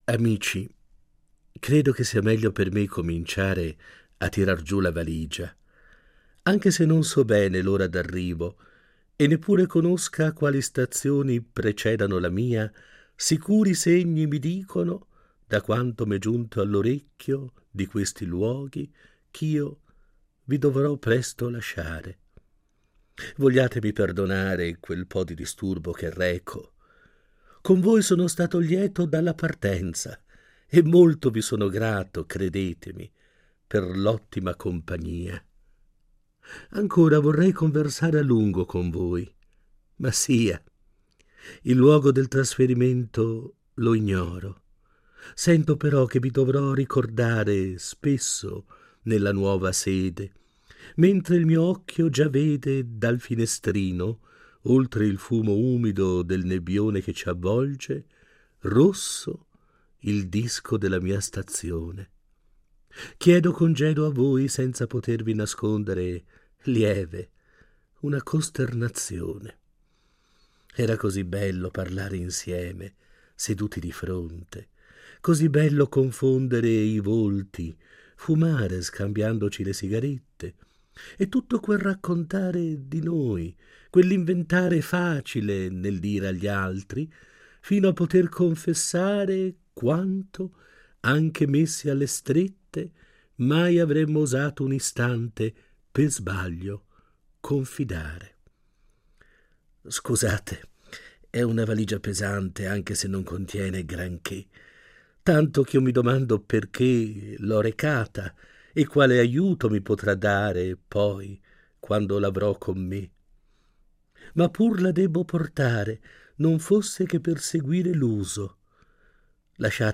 Il componimento che ascolteremo oggi, il celebre "Congedo del viaggiatore cerimonioso", è stato letto